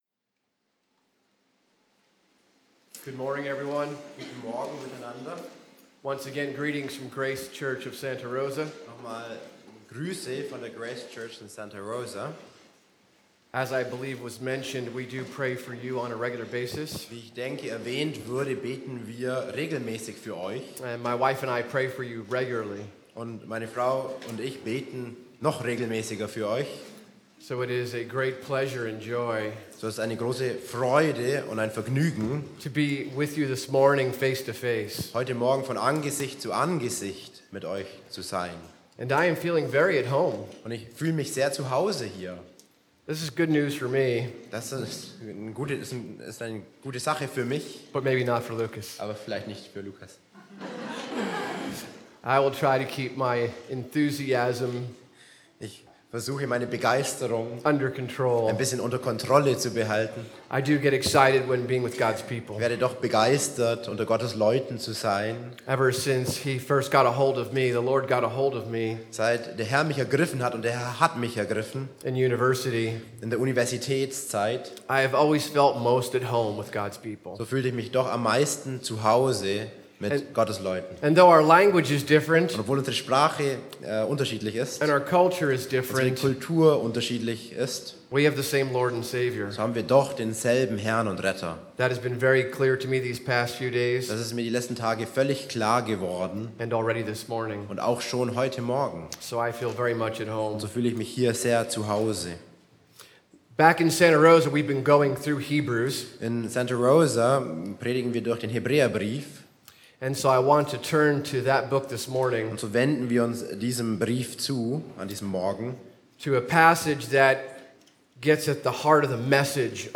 Gastprediger